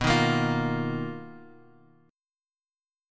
BM#11 Chord
Listen to BM#11 strummed